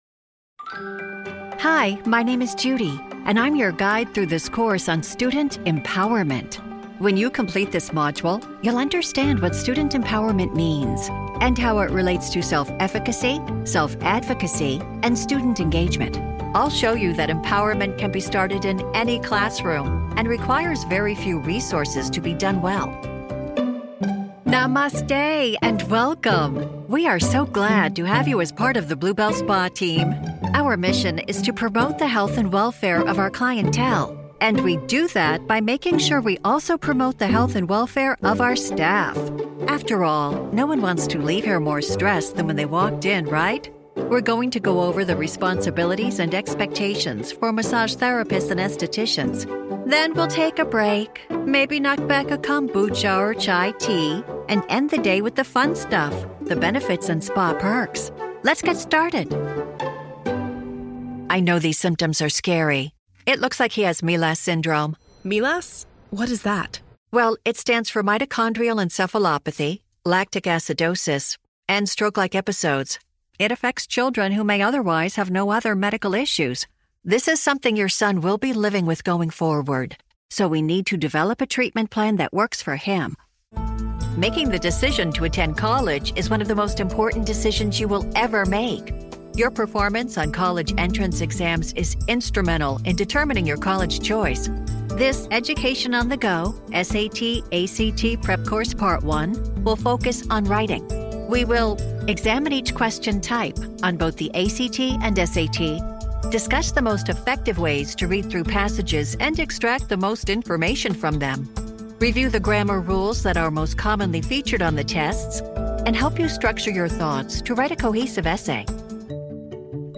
Elearning conversational, fun, authoritative medical, formal and kids
Middle Aged